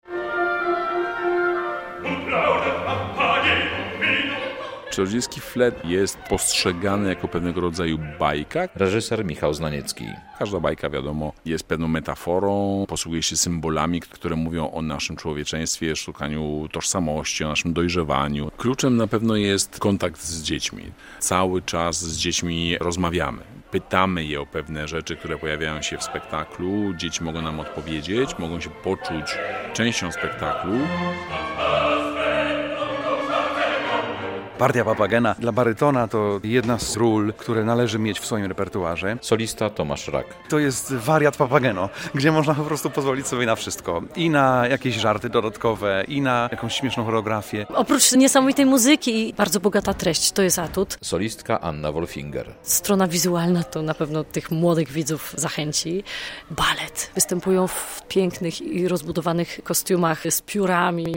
"Księgi zaczarowanego fletu"w Operze i Filharmonii Podlaskiej - relacja